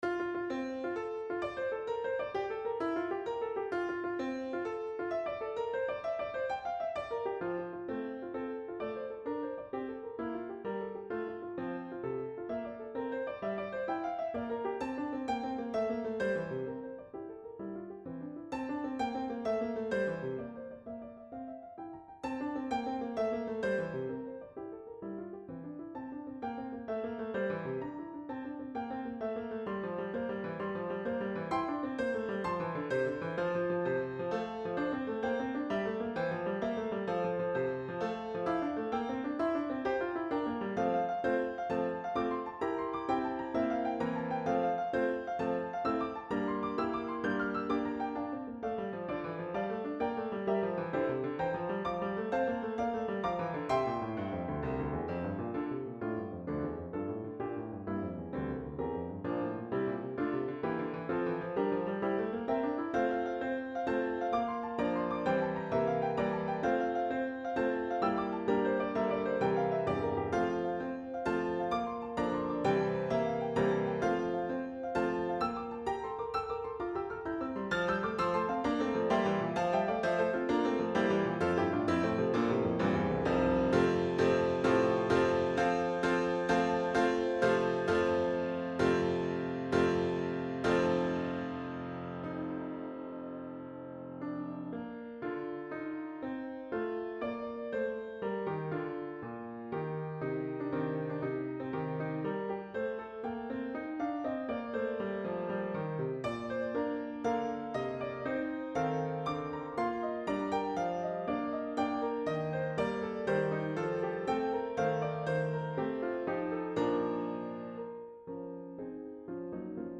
The overarching form of this music is ABA form. The A section is composed as an Irish jig.
Here the meter changes from 4/4 (four beats to a measure, the quarter note getting one beat) to 3/4 (three beats to a measure, the quarter note getting one beat). The music essentially changes from an Irish jig in quadruple meter to a waltz in triple meter.
The A melody then ends with a grand, majestic Coda.